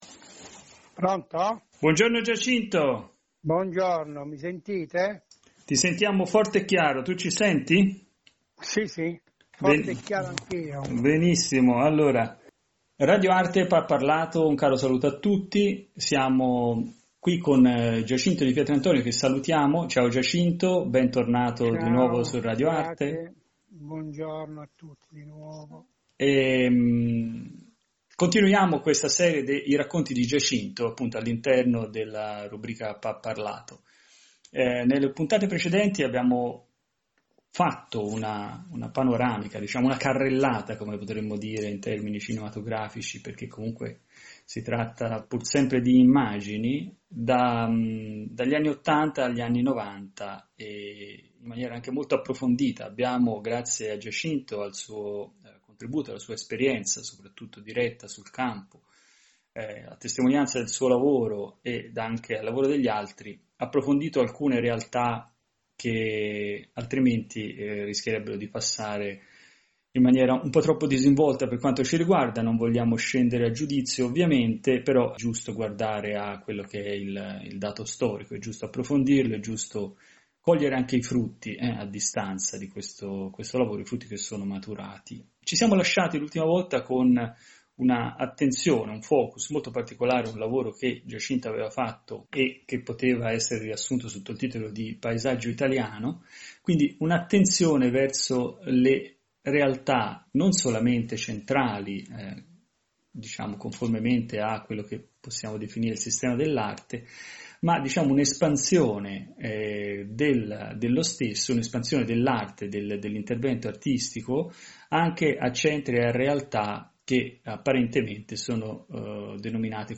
Pa-Parlato: Periodic interviews with the protagonists of the art world on topical themes.